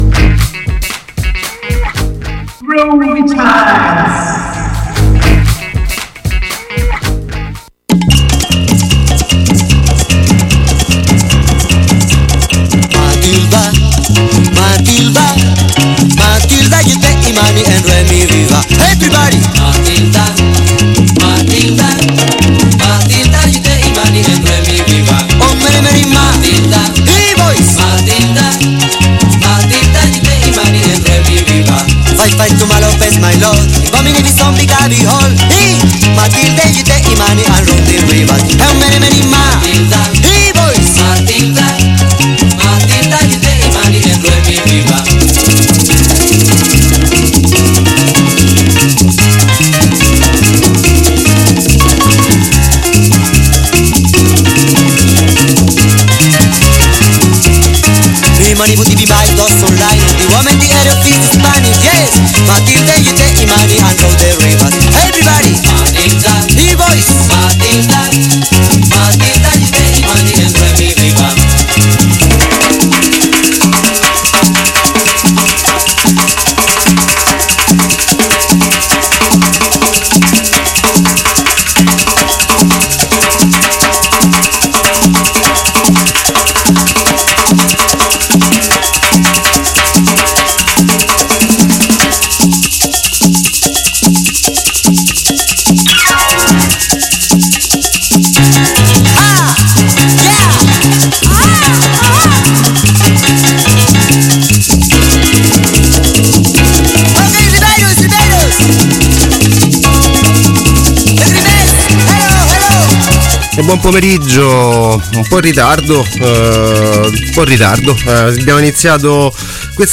afro-latina